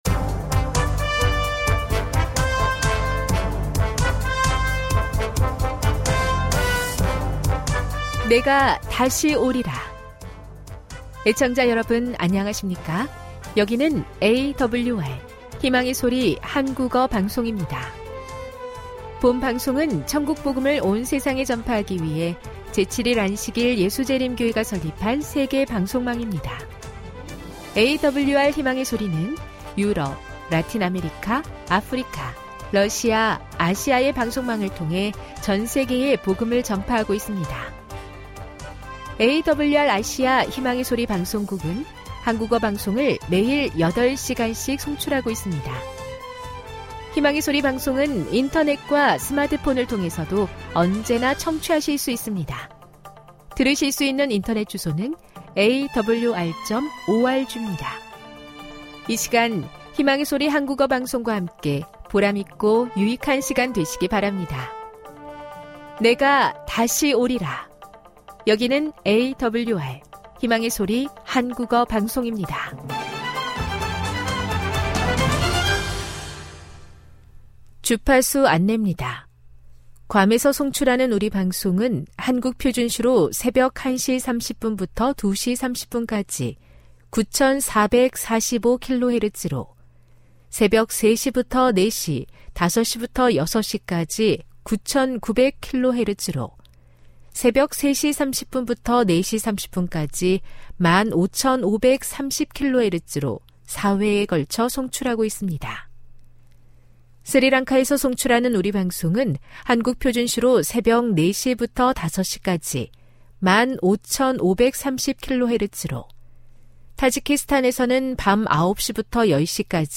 설교, 말씀묵상.